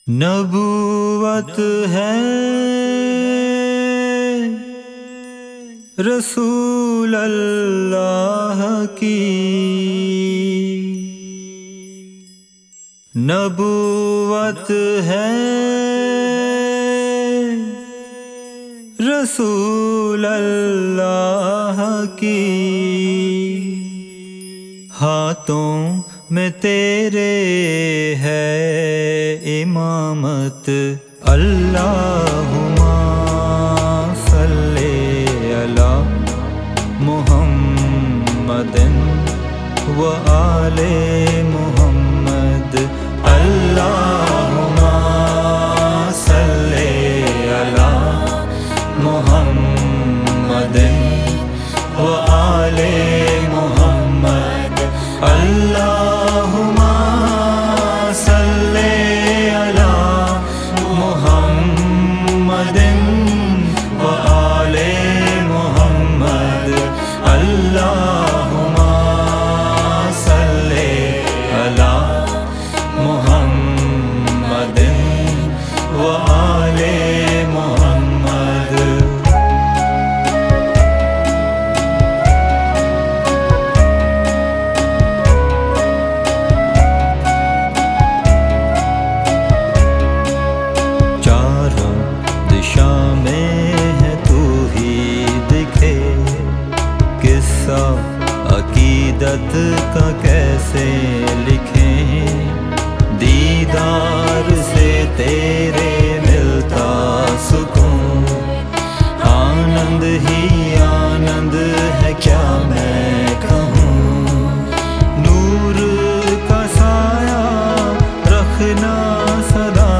musical rendering of the Salawat
and sung by numerous Ismaili artists.